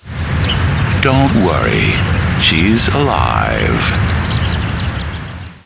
[BIZARRE TELEPHONE CALLS BEING RECEIVED BY SCOTLAND YARD! Available to THE LONDON SUN & NEWS OF THE WORLD readers only!]
Written transcripts of audio clues (TXT files) are available for users without sound-cards -- but where possible, we recommend downloading the sound files, because the background sounds and audio subtleties can be helpful in formulating your theory!
1600-Mystery Voice (WAV Format Sound File-62 KB)